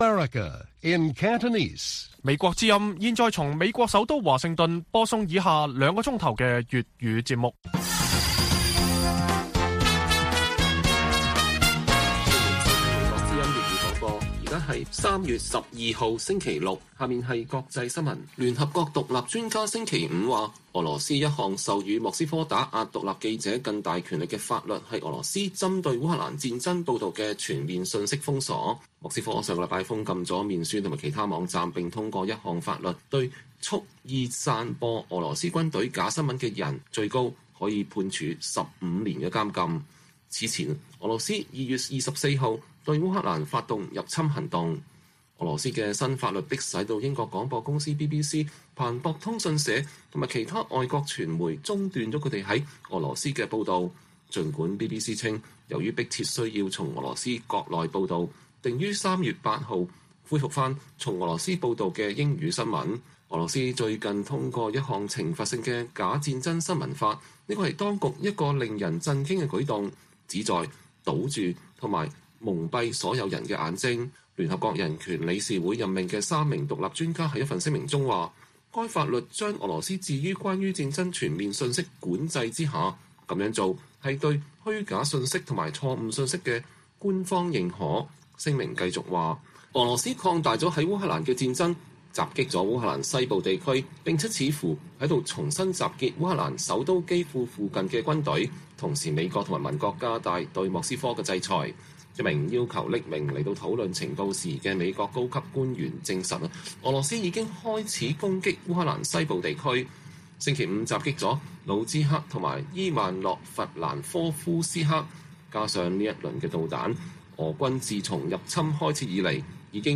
北京時間每晚9－10點 (1300-1400 UTC)粵語廣播節目。內容包括國際新聞、時事經緯和英語教學。